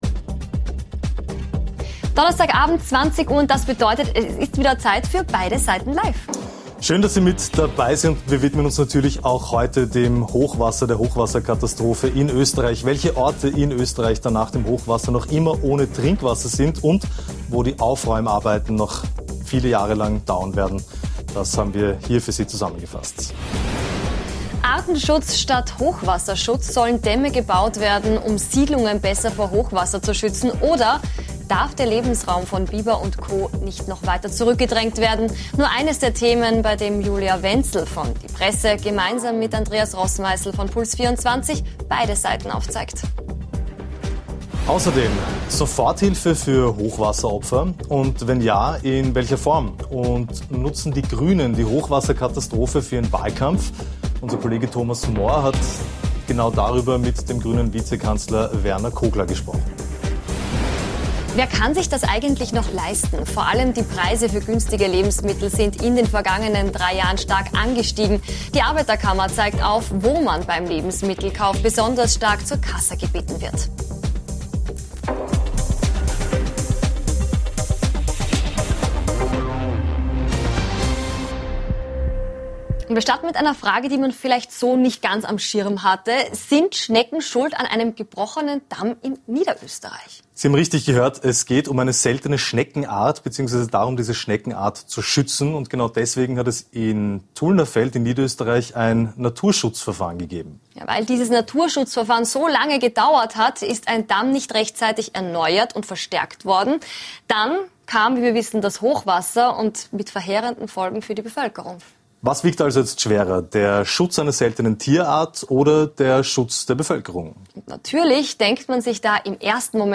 Zu Gast: Vizekanzler Werner Kogler, Die Grünen ~ Beide Seiten Live Podcast
Und nachgefragt haben wir heute bei Vizekanzler Werner Kogler, Die Grünen.